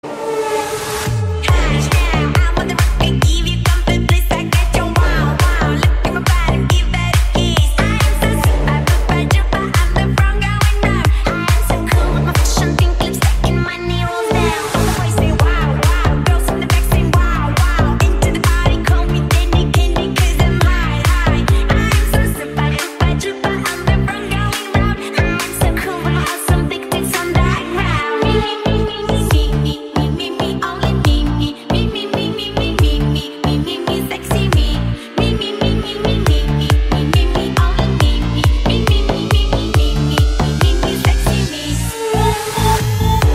Nhạc Chuông Remix